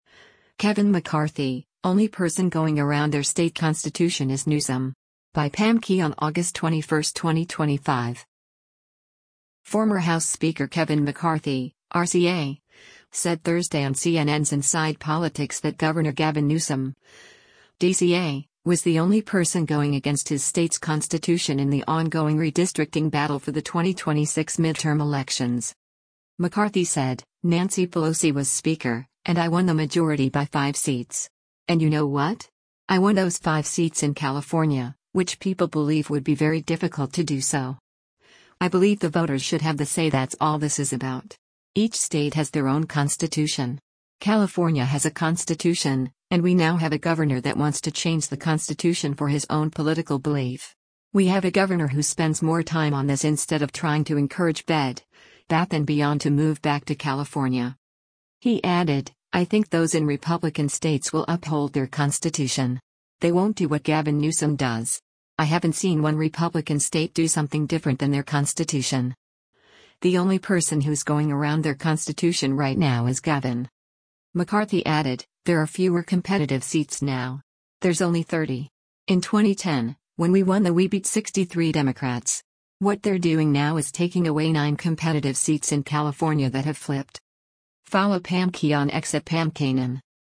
Former House Speaker Kevin McCarthy (R-CA) said Thursday on CNN’s “Inside Politics” that Gov. Gavin Newsom (D-CA) was the only person going against his state’s constitution in the ongoing redistricting battle for the 2026 midterm elections.